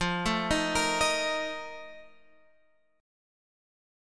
F 11th